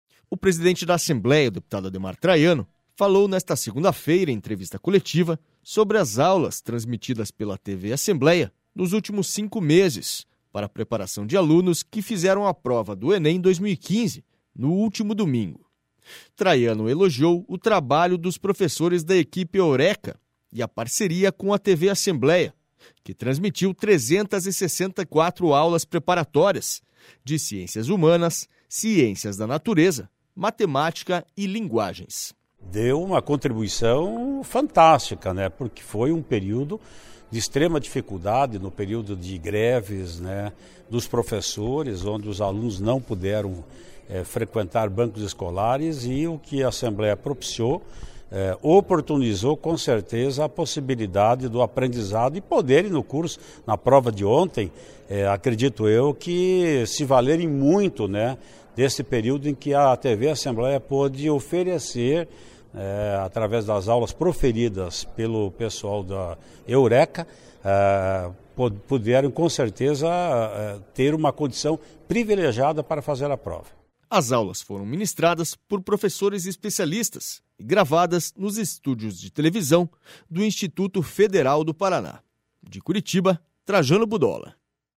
O presidente da Assembleia, deputado Ademar Traiano, falou nesta segunda-feira em entrevista coletiva sobre as aulas transmitidas pela TV Assembleia nos últimos cinco meses para a preparação de alunos que fizeram a prova do Enem 2015, no último domingo.//
SONORA ADEMAR TRAIANO